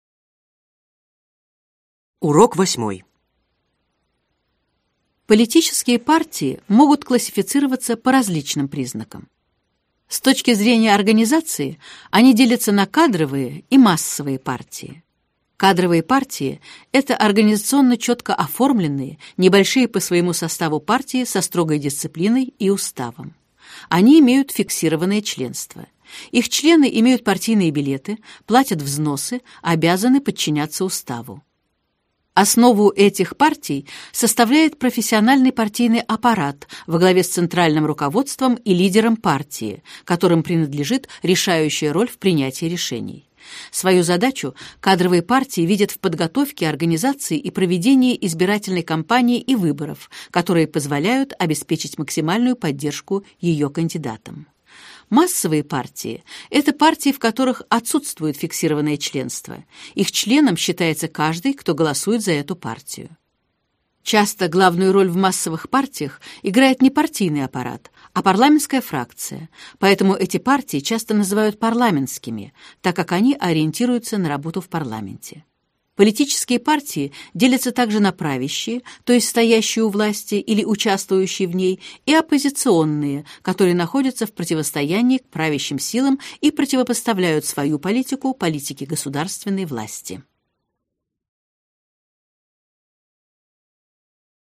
Аудиокнига Выпуск 7. Политология | Библиотека аудиокниг